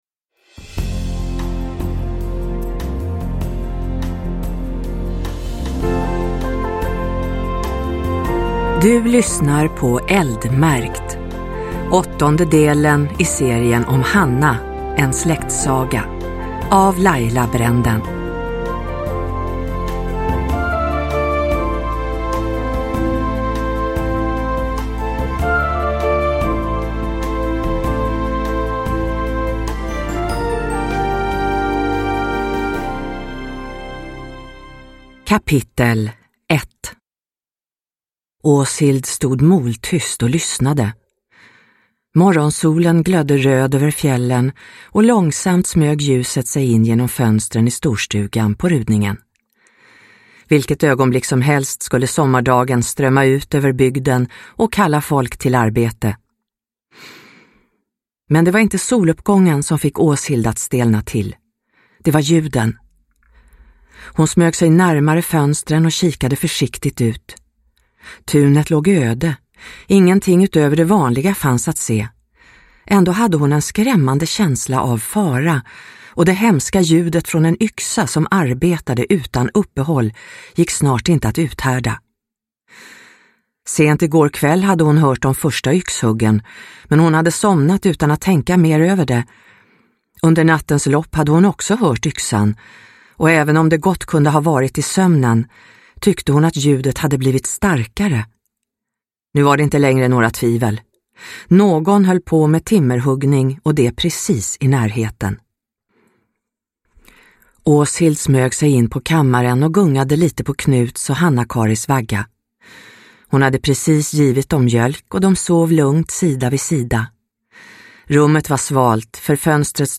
Eldmärkt – Ljudbok – Laddas ner